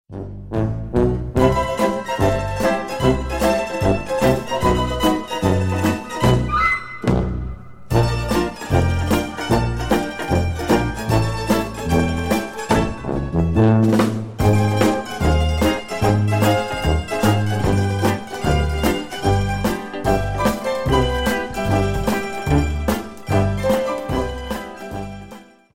Весёлые Рингтоны
Рингтоны Без Слов